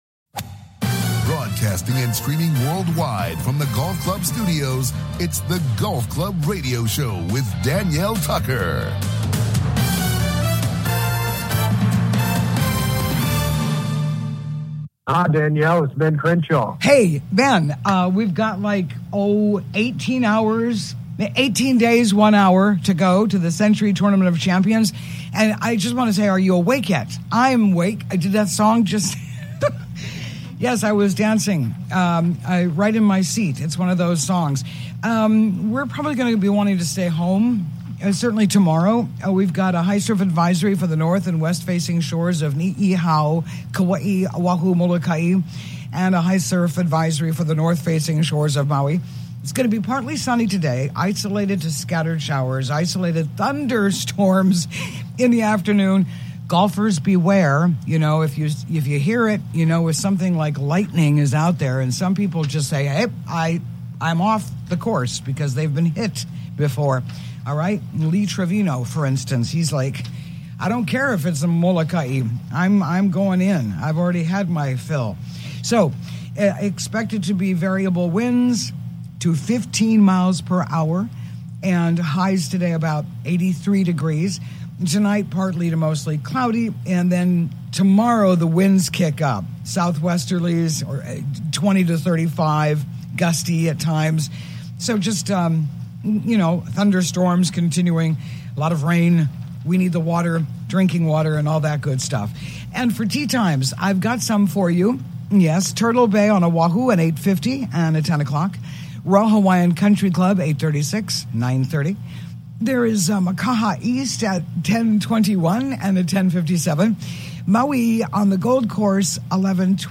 COMING TO YOU LIVE FROM THE GOLF CLUB STUDIOS ON LOVELY OAHU�s SOUTH SHORE � WELCOME INTO THE GOLF CLUB HOUSE!